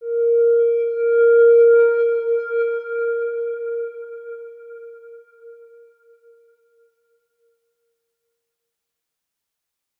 X_Windwistle-A#3-mf.wav